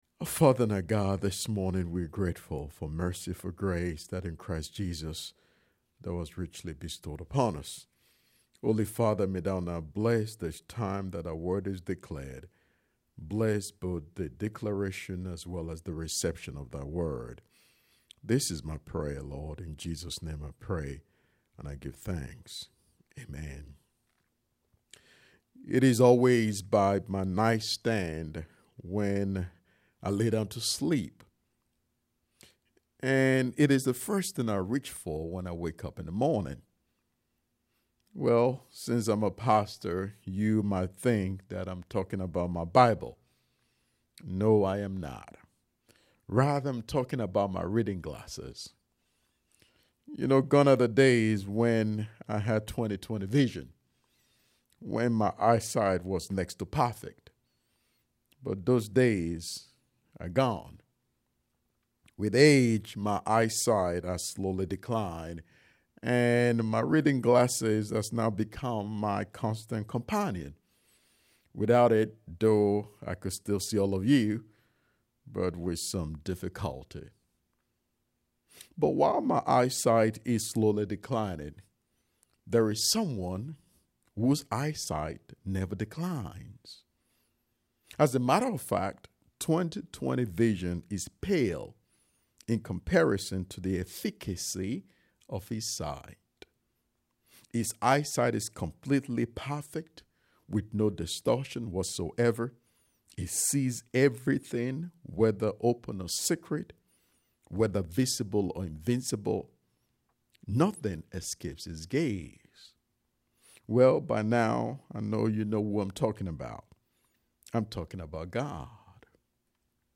10:30 AM Service I’ve Got My Eyes on You Click to listen to the sermon. https